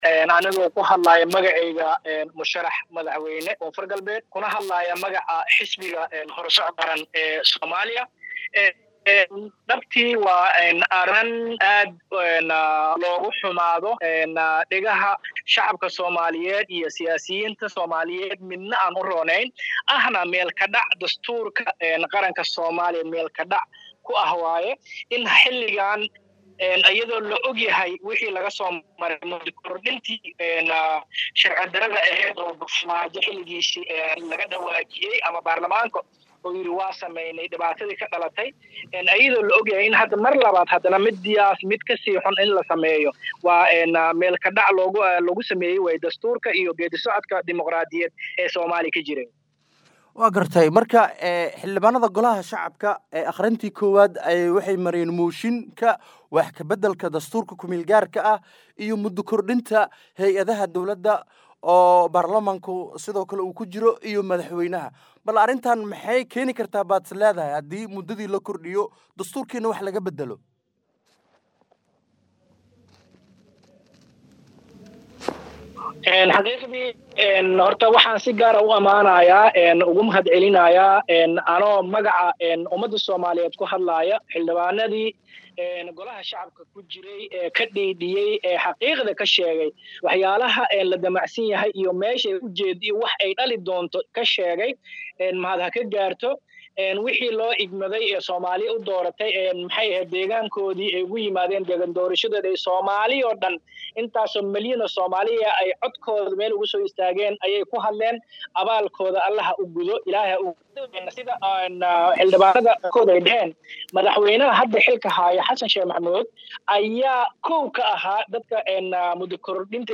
Halkan Hoose ka Dhageyso waraysiga